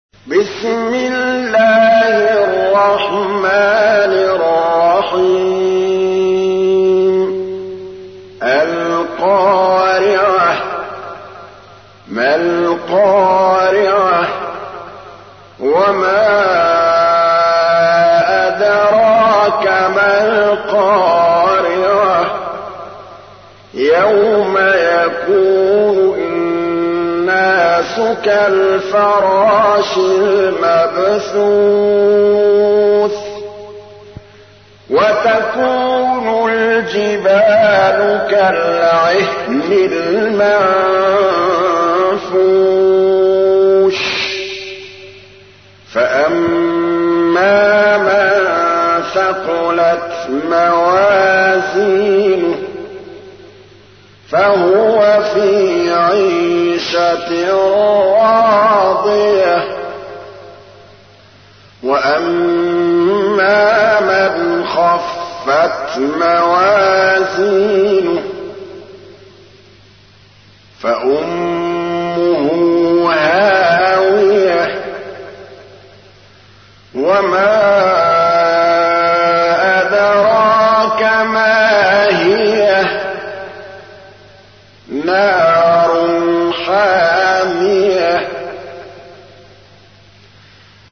تحميل : 101. سورة القارعة / القارئ محمود الطبلاوي / القرآن الكريم / موقع يا حسين